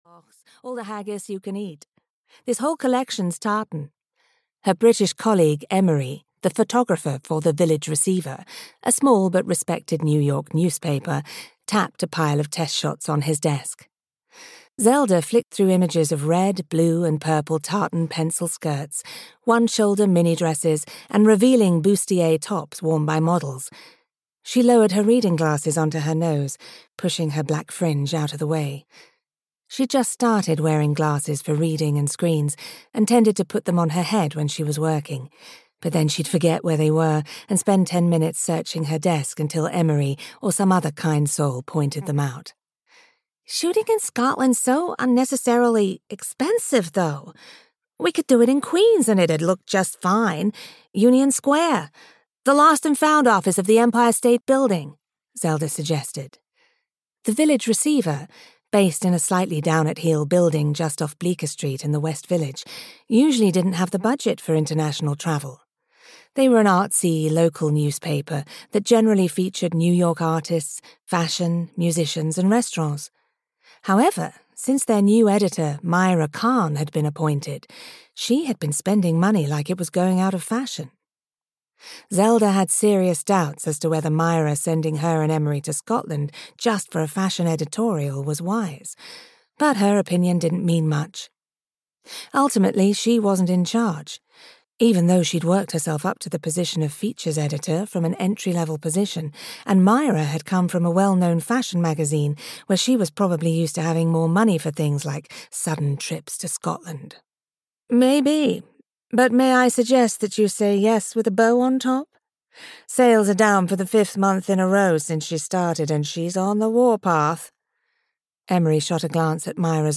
Audio knihaThe Cottage by the Loch (EN)
Ukázka z knihy